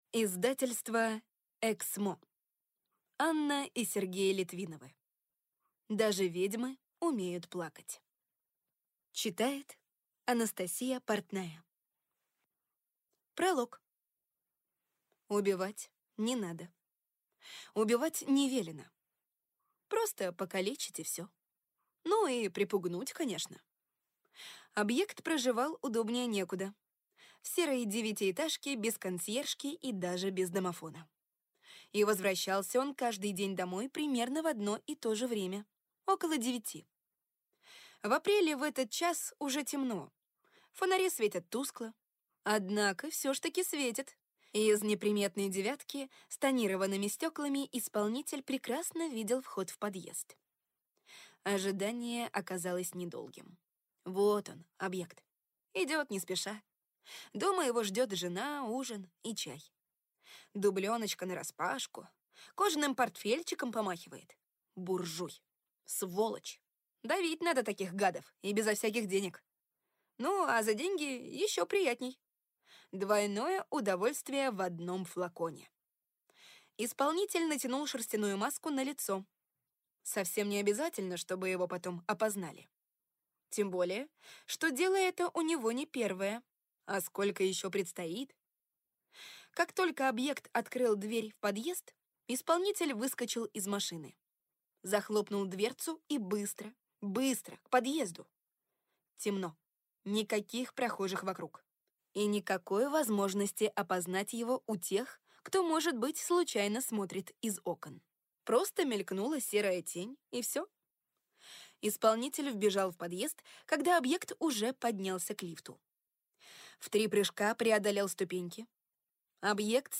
Аудиокнига Даже ведьмы умеют плакать | Библиотека аудиокниг